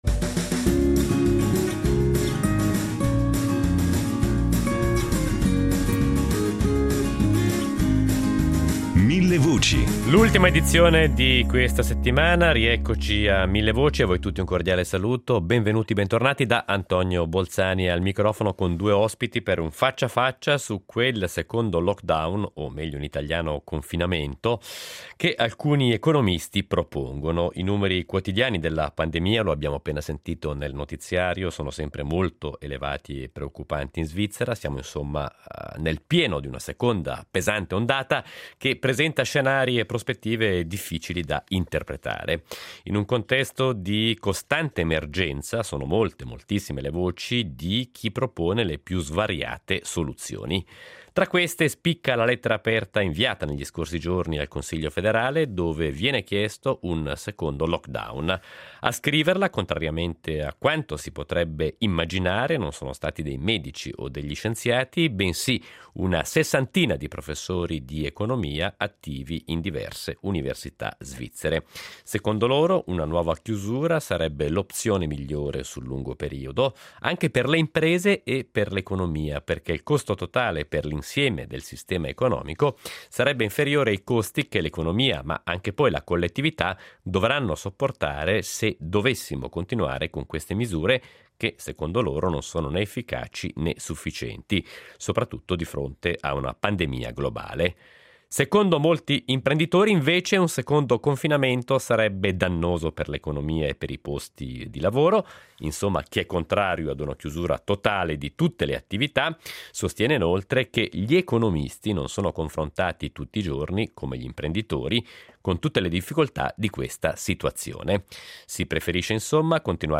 Le opinioni, su questa opzione, divergono e noi oggi a Millevoci vi proponiamo un faccia a faccia su questo tema.